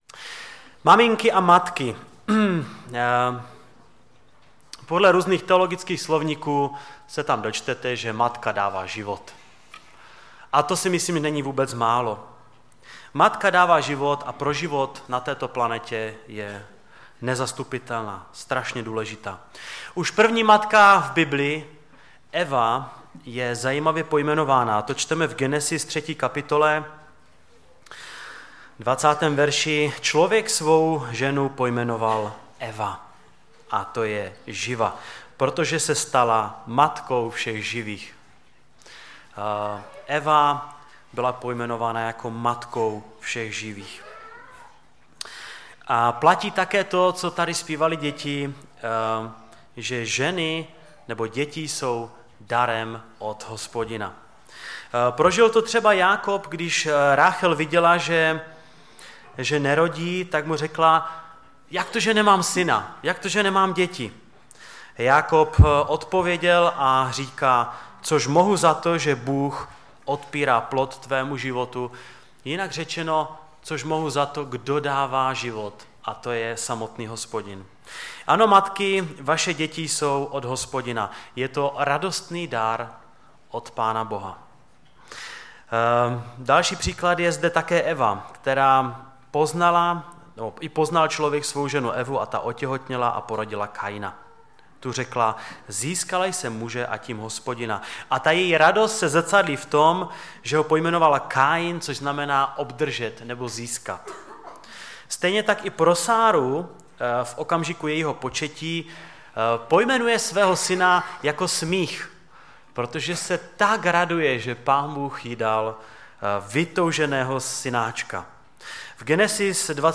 Kázání
ve sboře Ostrava-Radvanice, při příležitosti programu pro maminky.